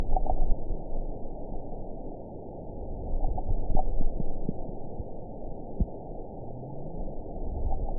event 917163 date 03/22/23 time 14:37:50 GMT (2 years, 7 months ago) score 8.93 location TSS-AB05 detected by nrw target species NRW annotations +NRW Spectrogram: Frequency (kHz) vs. Time (s) audio not available .wav